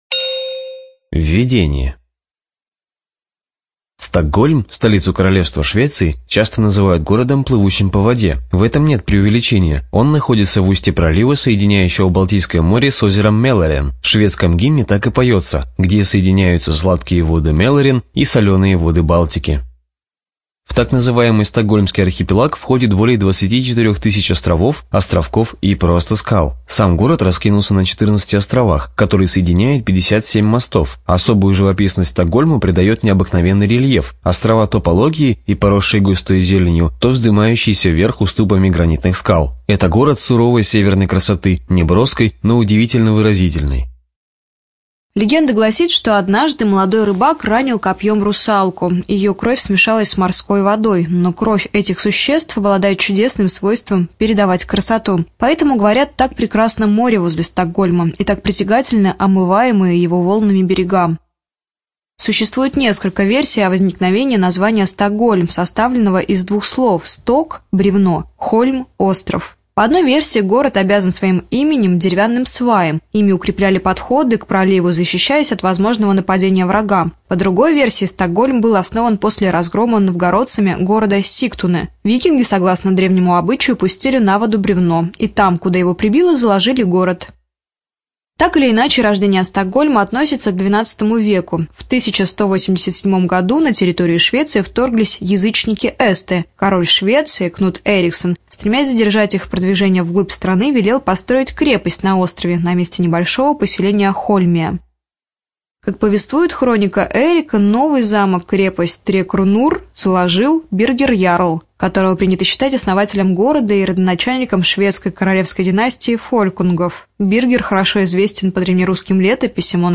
ЖанрАудиоэкскурсии и краеведение
Студия звукозаписиРоссийская государственная библиотека для слепых